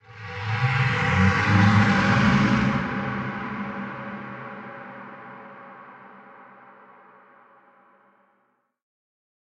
Sfx_amb_unknowncreatures_bigsnake_01.ogg